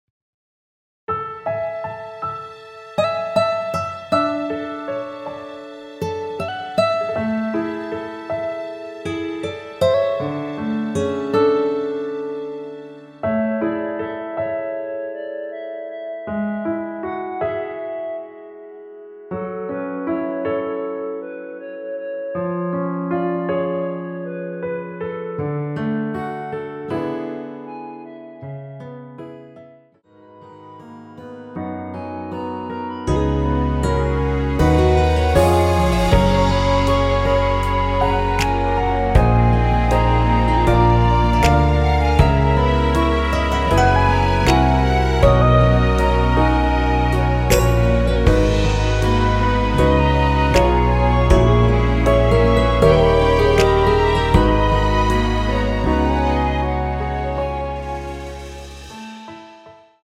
원키에서(+5)올린 멜로디 포함된 MR입니다.(미리듣기 확인)
앞부분30초, 뒷부분30초씩 편집해서 올려 드리고 있습니다.